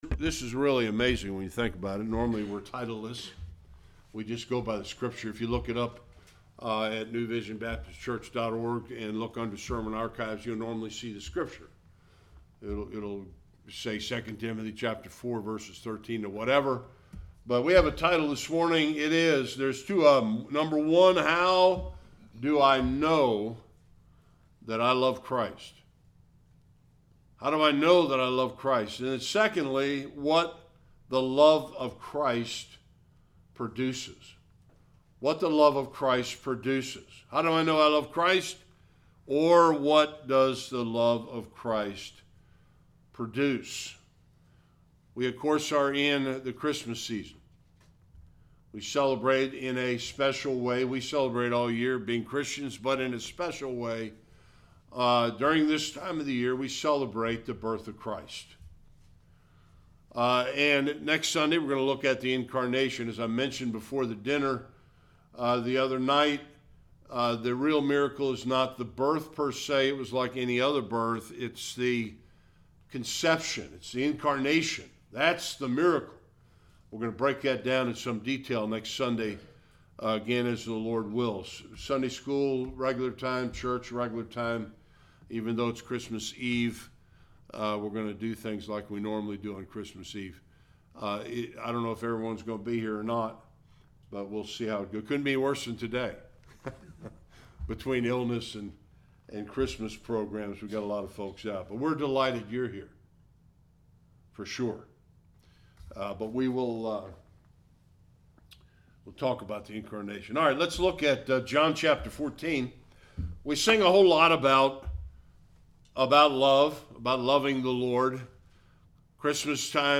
Various Passages Service Type: Sunday Worship One who loves Christ lives a life markedly different from one who doesn’t. Topics